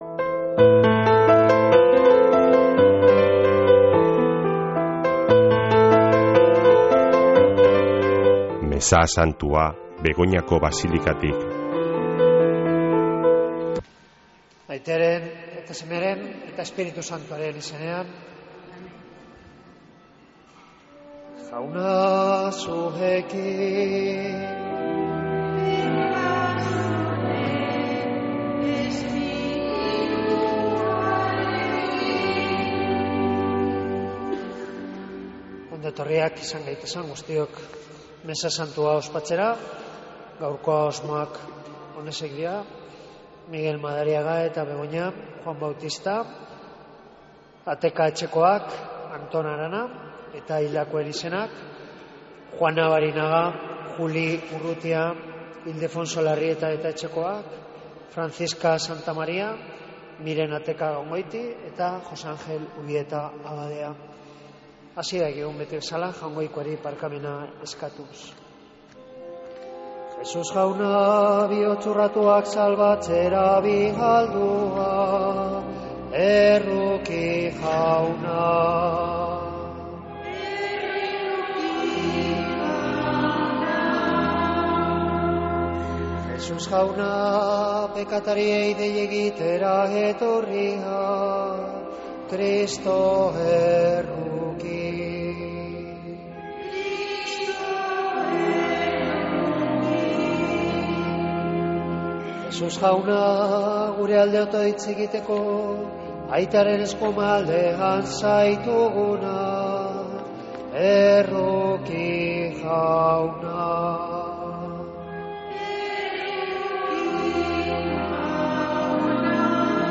Mezea (24-11-11)